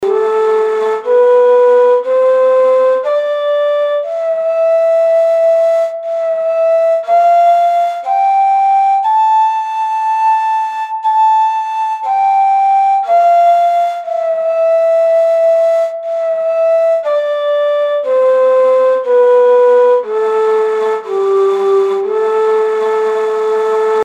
Ney – Buselik Makamı | Üsküdar Makam Atölyesi - Makam ve Musiki Dersleri
Tür: Türk Musikisi.
ney-buselik-makami